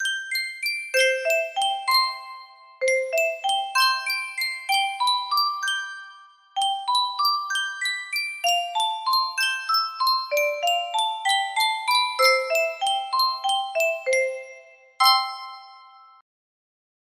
Yunsheng Music Box - Down in the Valley 5964 music box melody
Full range 60